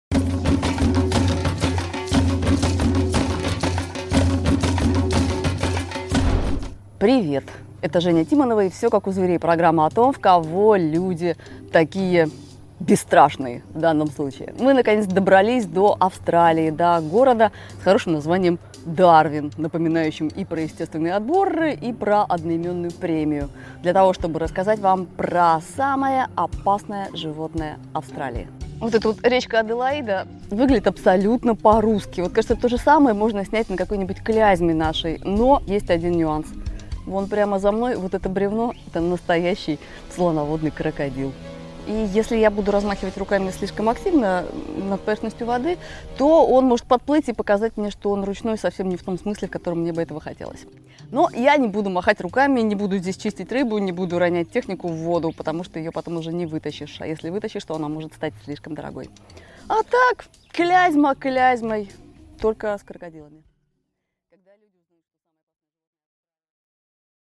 Аудиокнига Чак Норрис среди крокодилов | Библиотека аудиокниг